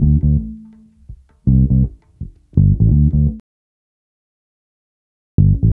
Bass Lick 35-04.wav